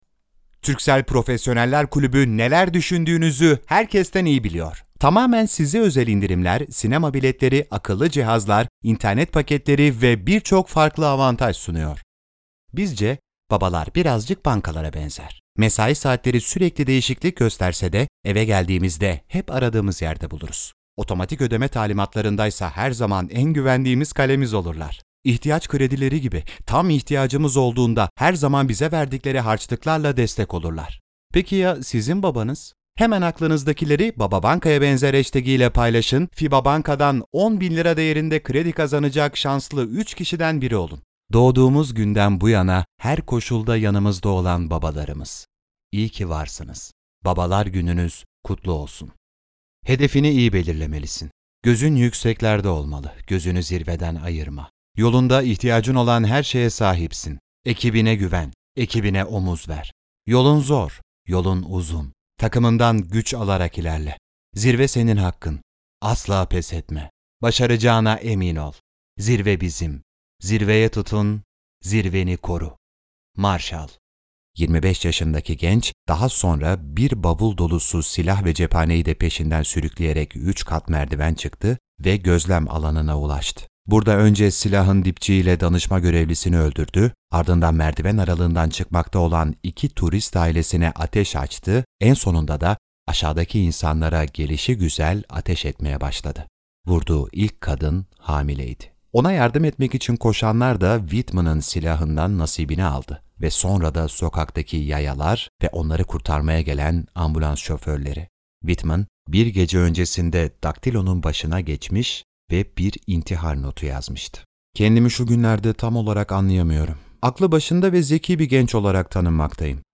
Seslendirme